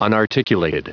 Prononciation du mot unarticulated en anglais (fichier audio)
Prononciation du mot : unarticulated